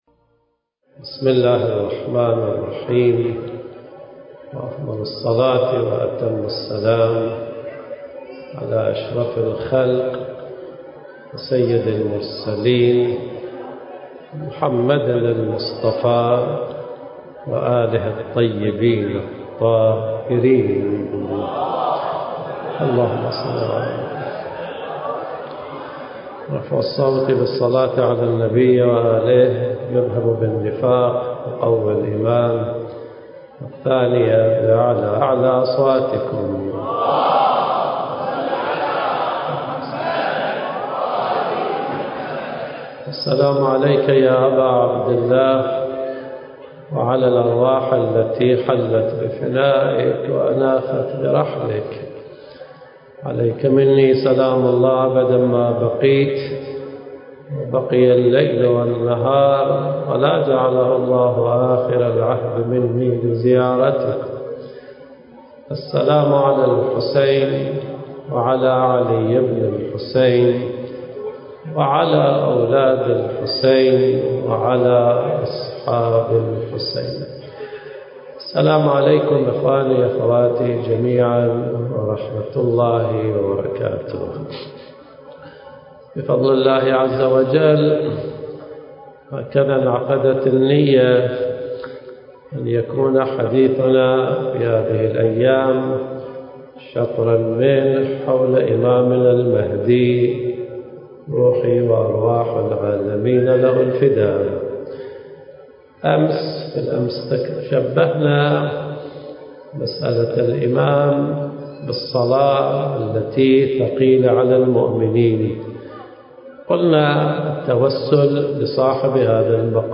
برنامج: في رحاب عرفة الحسين (عليه السلام) المكان: العتبة الحسينية المقدسة التاريخ: 2023